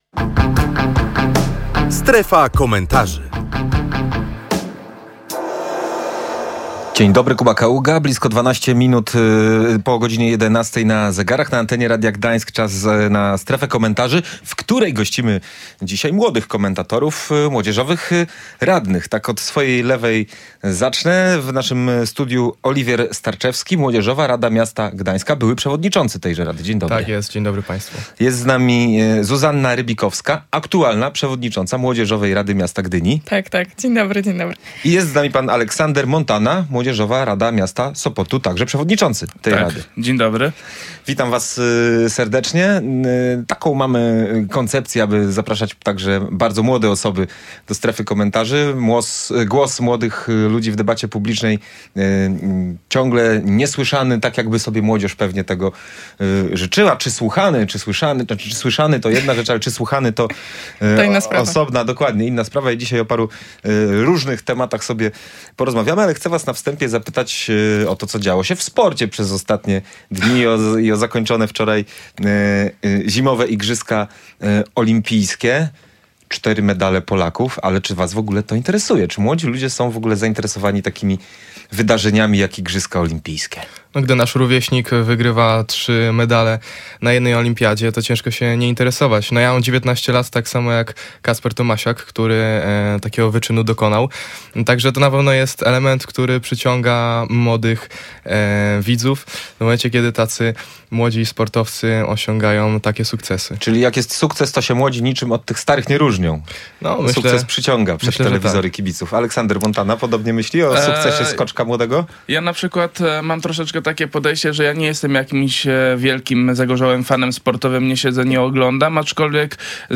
Młodzieżowi radni w „Strefie Komentarzy”